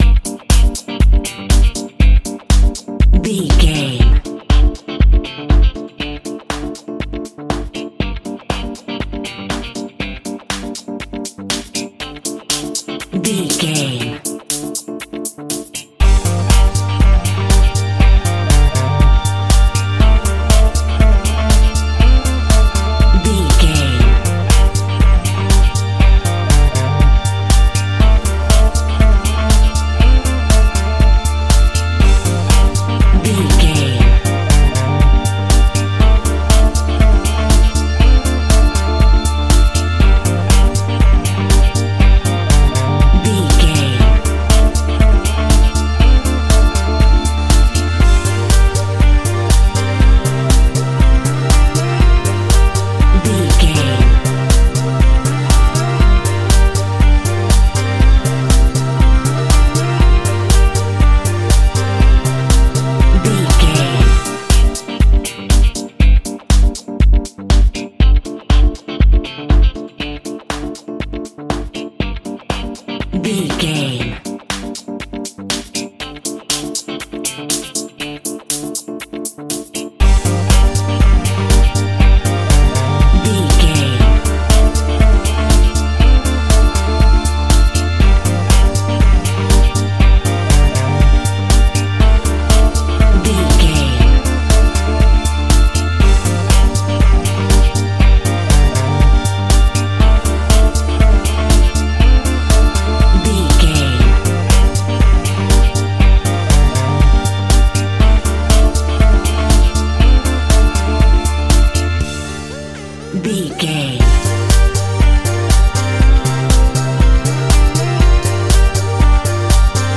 Funky Groove Electro Music.
Aeolian/Minor
groovy
uplifting
energetic
drums
bass guitar
electric guitar
synthesiser
strings
electric piano
funky house
upbeat
instrumentals